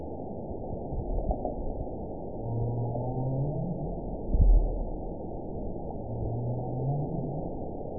event 916218 date 12/27/22 time 12:08:21 GMT (2 years, 5 months ago) score 6.63 location INACTIVE detected by nrw target species NRW annotations +NRW Spectrogram: Frequency (kHz) vs. Time (s) audio not available .wav